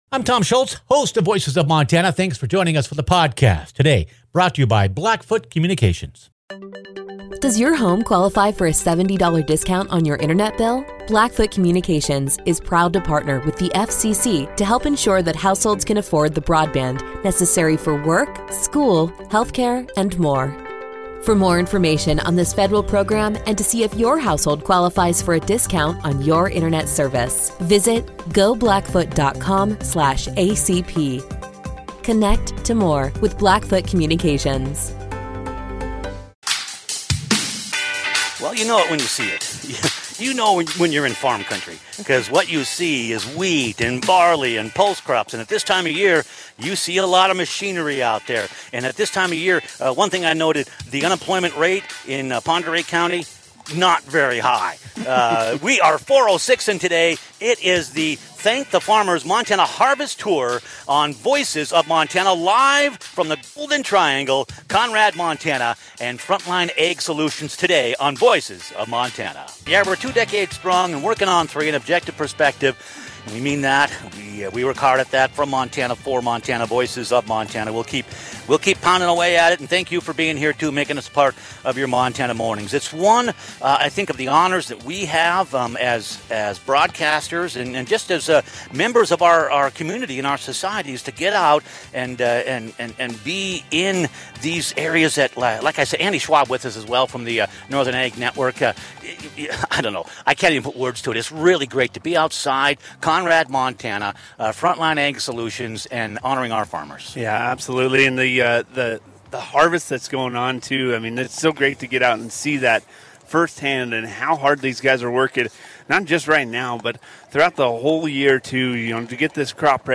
Live from Conrad and the Golden Triangle - Voices of Montana